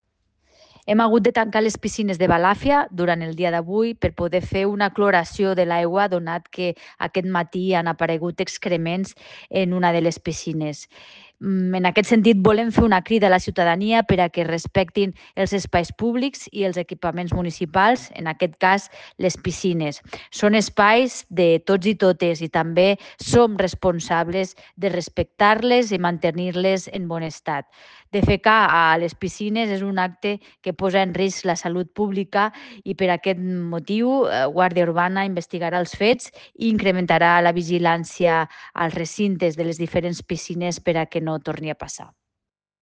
Talls de veu
Tall de veu del director de la Institució de les Lletres Catalanes, Eduard Escoffet